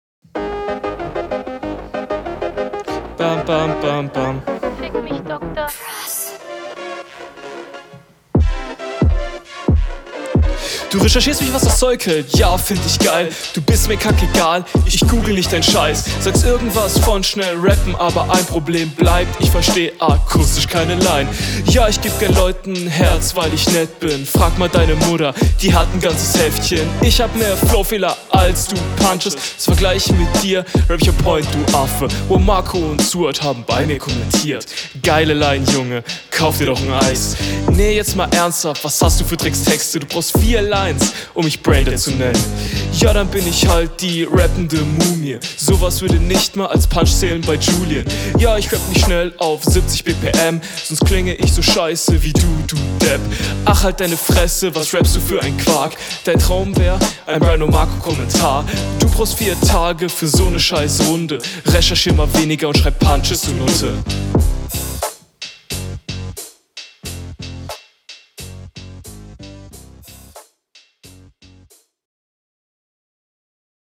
In allen Sachen bist du deinem Gegner überlegen. bisschen zuviel Echo und Hall …